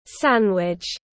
Bánh mì kẹp tiếng anh gọi là sandwich, phiên âm tiếng anh đọc là /ˈsænwɪdʒ/
Sandwich /ˈsænwɪdʒ/